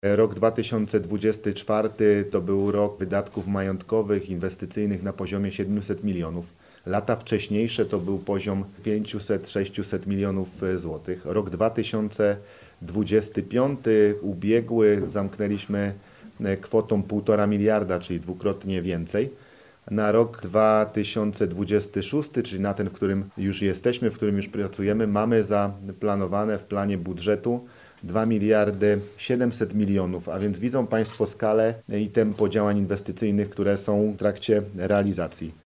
– Jesteśmy w wyjątkowym momencie infrastrukturalnej historii naszego województwa – mówi Paweł Gancarz, marszałek Województwa Dolnośląskiego.